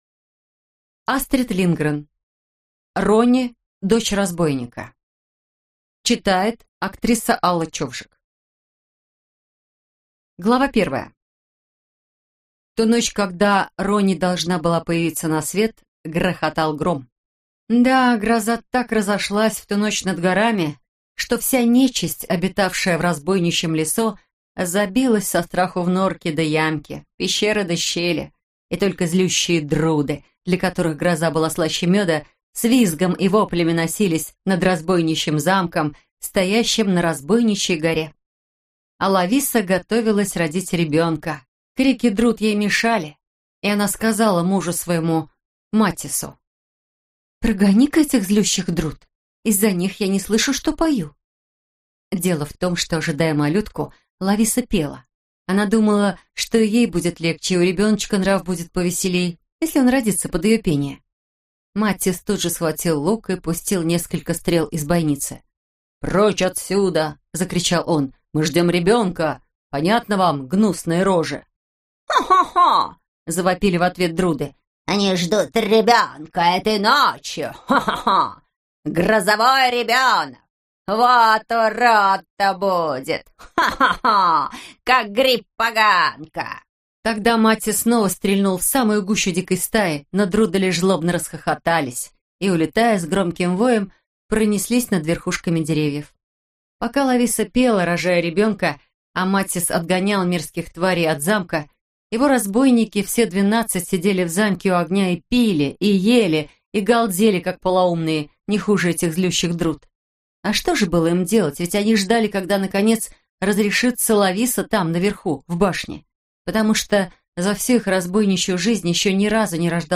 Аудиокнига Рони, дочь разбойника - купить, скачать и слушать онлайн | КнигоПоиск